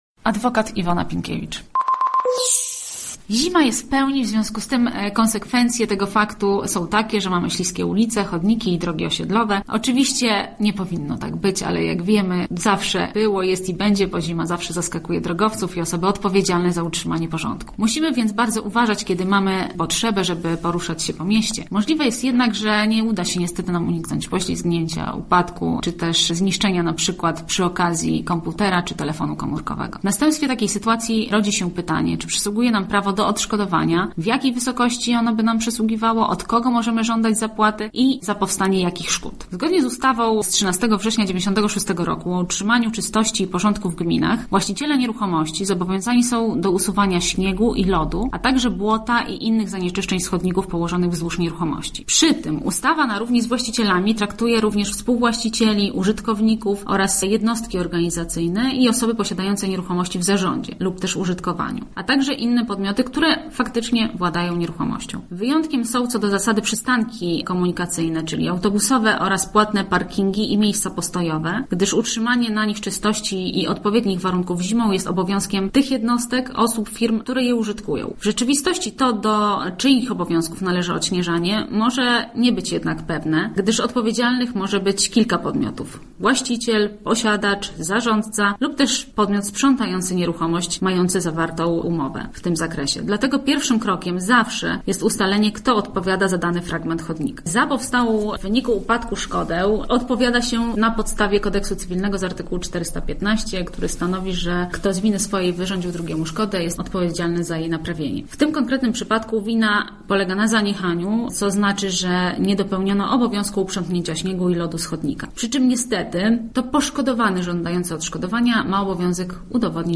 adwokat.mp3